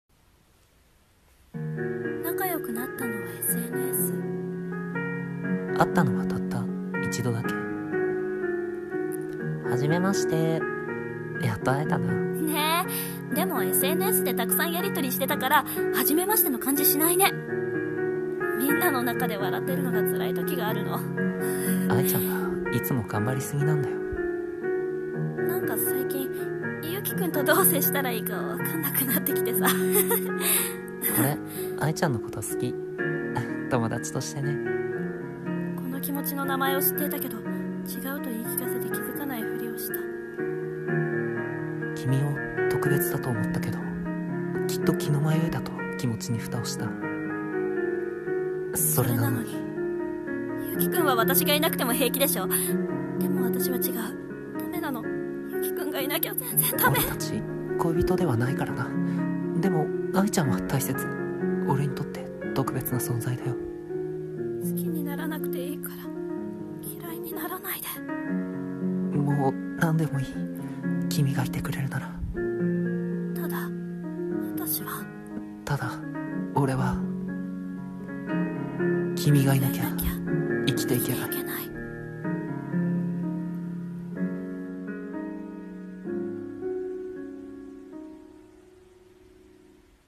CM風声劇「共依存